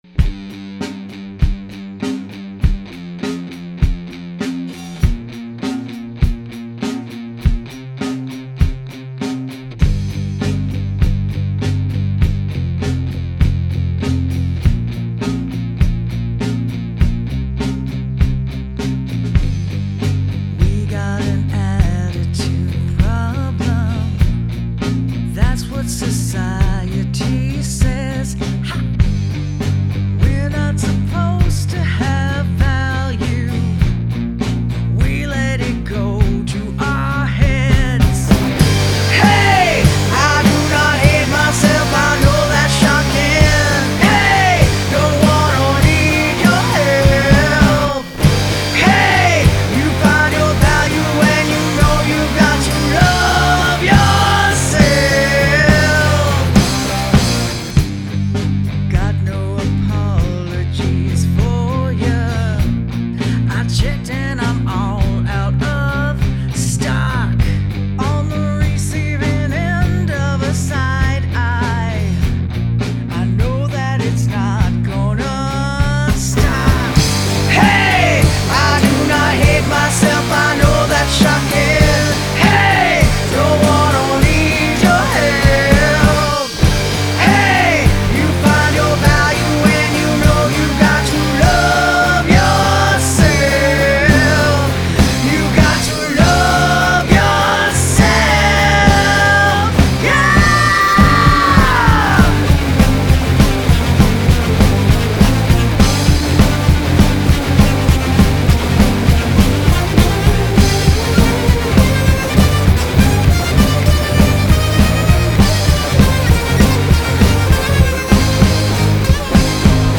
The harmonies are great.
Love that snarl. This chorus is huge.
A headbanger for certain.